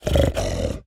Minecraft Version Minecraft Version snapshot Latest Release | Latest Snapshot snapshot / assets / minecraft / sounds / mob / piglin_brute / idle2.ogg Compare With Compare With Latest Release | Latest Snapshot